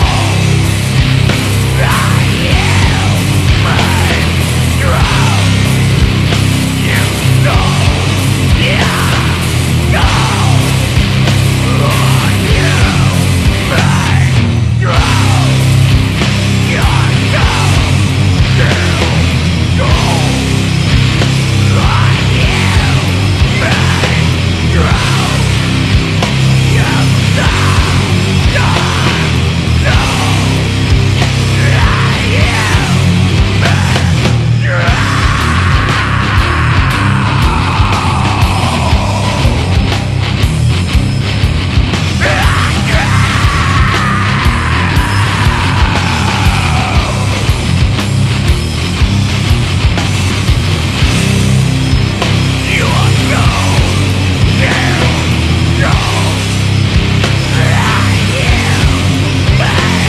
STONER ROCK / POST ROCK
轟音ポストロック/サイケデリック・ストーナー・ロック！